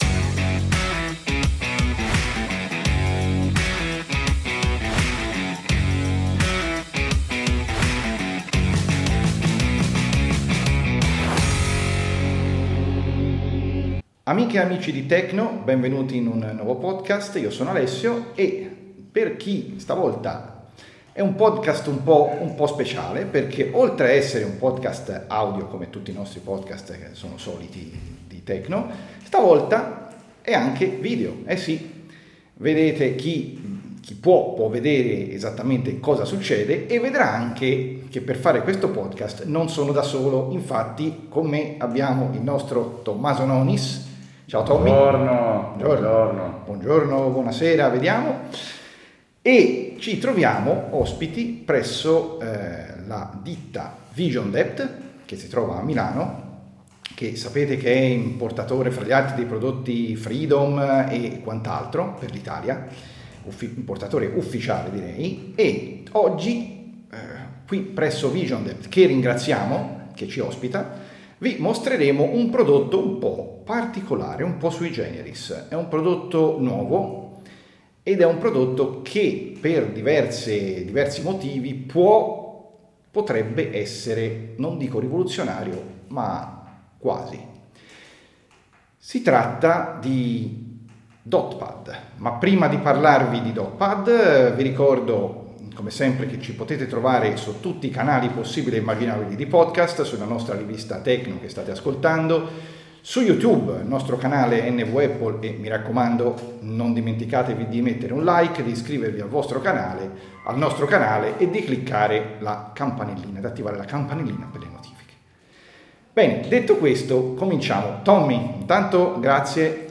live dagli uffici della Vision Dept di Milano